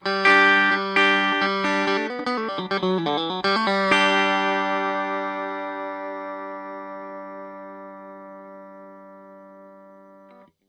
描述：电吉他即兴演奏了汤姆安德森吉他。
标签： 电吉他 摇滚 吉他
声道立体声